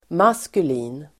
Ladda ner uttalet
Uttal: [m'as:kuli:n (el. -'i:n)]
maskulin.mp3